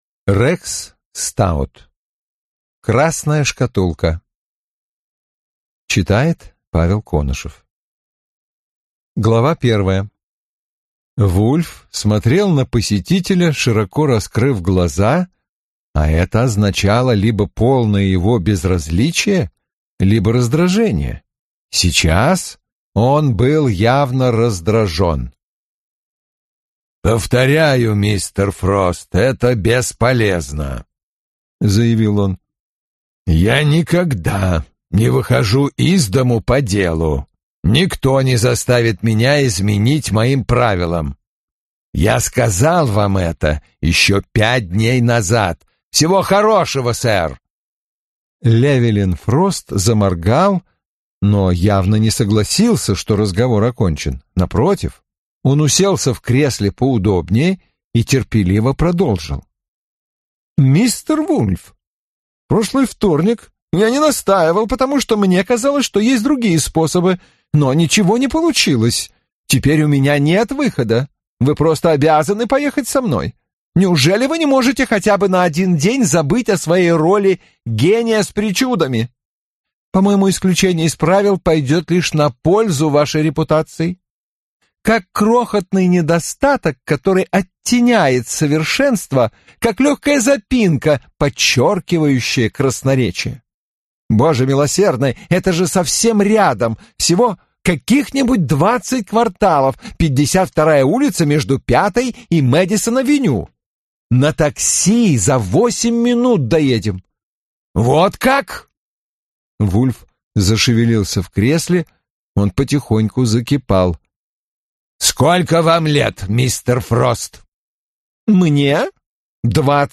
Аудиокнига Красная шкатулка | Библиотека аудиокниг